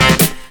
DRUMFILL12-L.wav